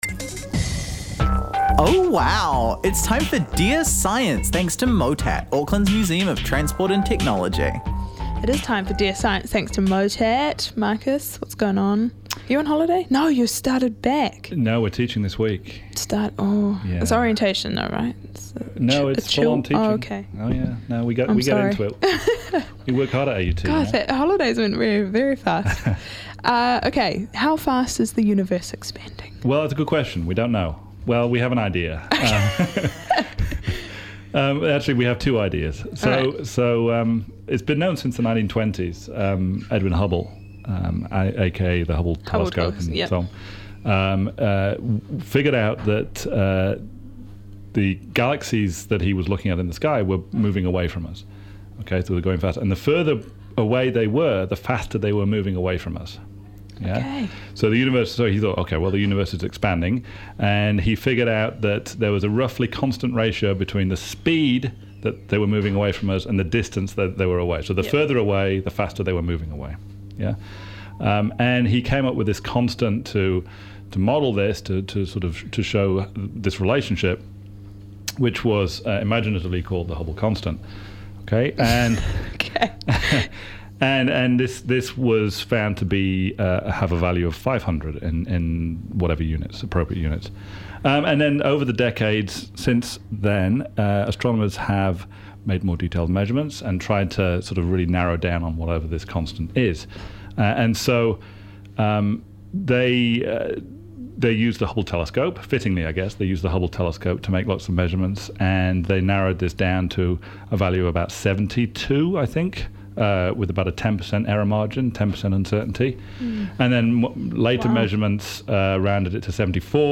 In our final weekly chat to Māori party co-leader Marama Fox for 2016, we discuss 2016 in politics, what to look forward to in 2017, and even get a special impromptu performance of 'Santa Baby'.